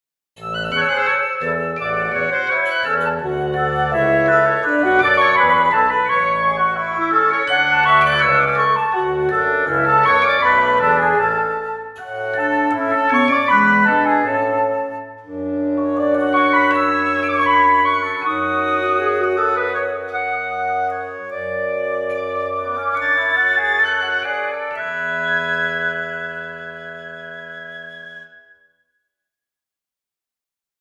フルート[エアリード・中～高音域]
・息の安定性は低いので変化が急でダイナミクスの幅も大きい
・音の立ち上がりは鋭く短い
クラリネット[シングルリード・低～高音域]
オーボエ[ダブルリード・中～高音域]
バスーン[ダブルリード・低～中音域]
・息の安定性が高くダイナミクスは滑らかだがオーボエほど安定しない
・後半の長音ではアタック音を消すためにノートを分けず繋げている(タンギング的処理)